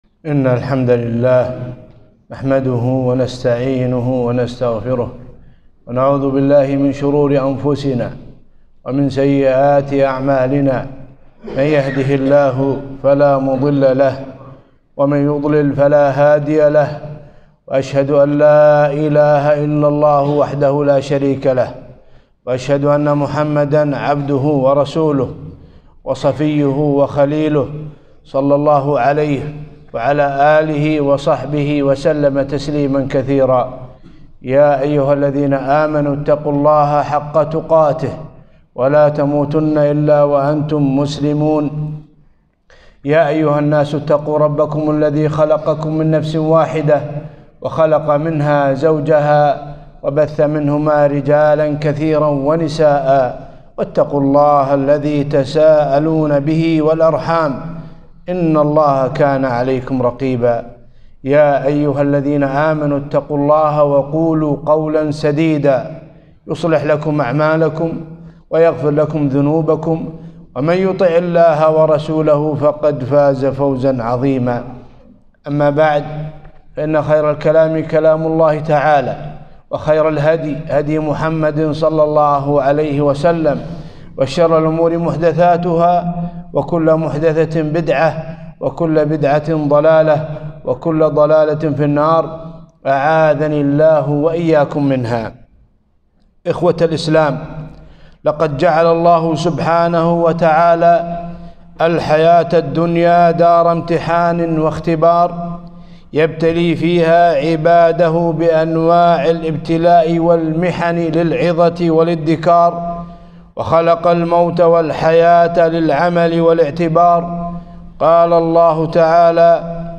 خطبة - الوقاية من الأمراض